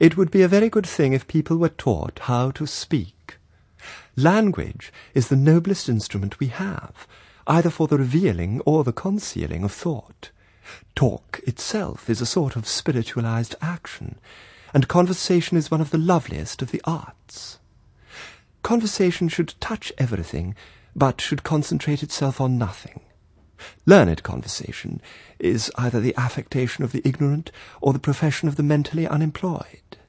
Reading 2: